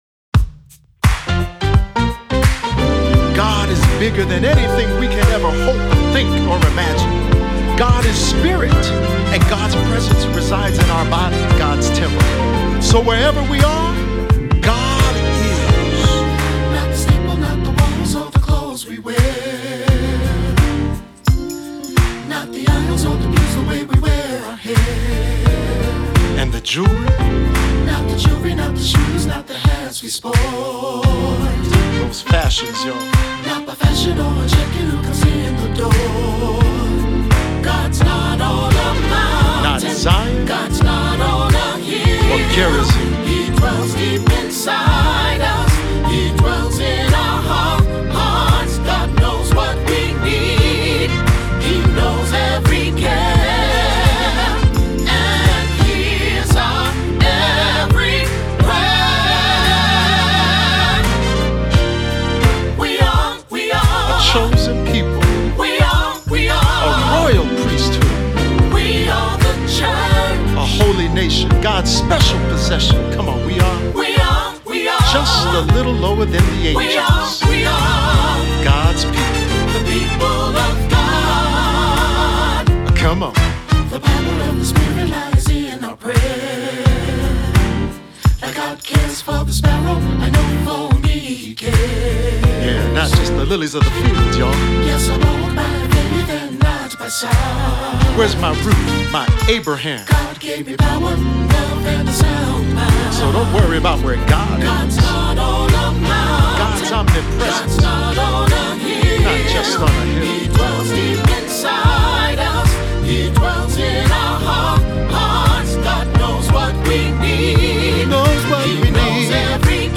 Voicing: "SAT","Solo"